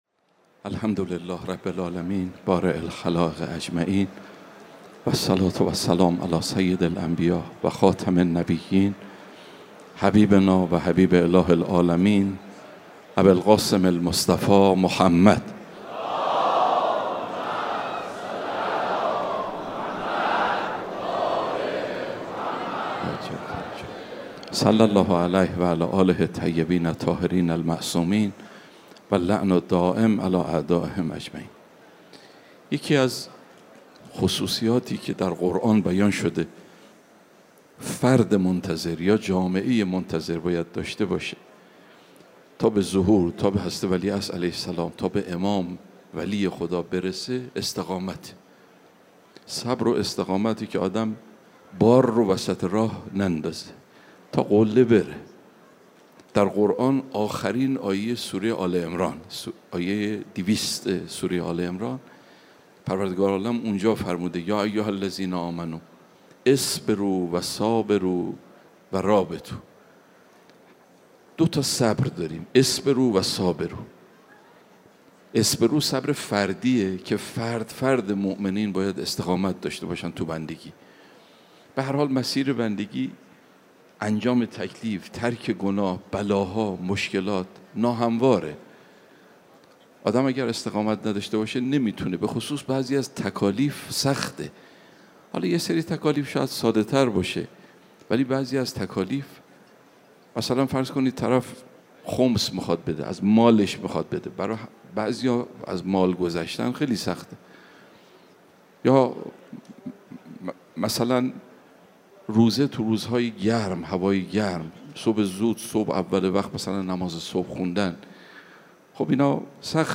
سخنرانی دهه اول محرم 1402